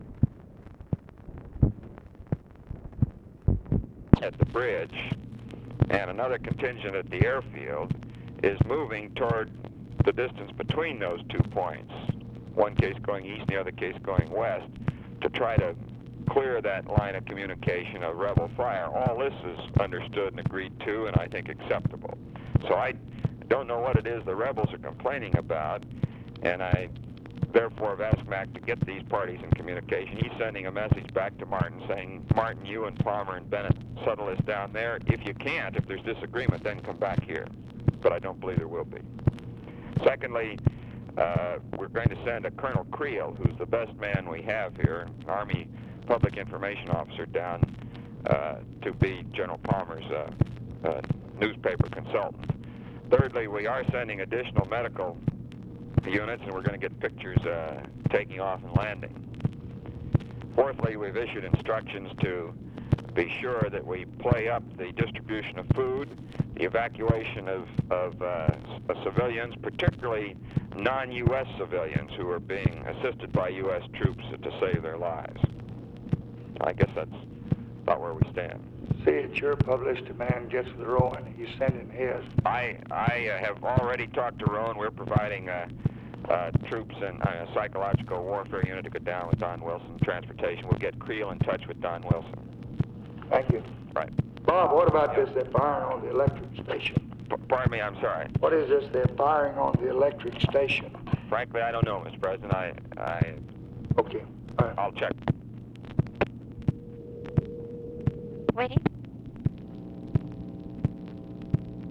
Conversation with ROBERT MCNAMARA, May 1, 1965
Secret White House Tapes